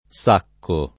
S#kko] s. m. — pl. i sacchi; antiq. le sacca (es.: quando gli ho mandato un par di sacca di grano [kU#ndo l’l’ 0 mmand#to um p#r di S#kka di gr#no], Pratesi), usabile ancóra in qualche locuz. come a sacca (o a sacchi), «in abbondanza», o in qualche proverbio come chi ha il grano non ha le sacca — sim. i top. le Sacca (Tosc.), Sacco e i cogn.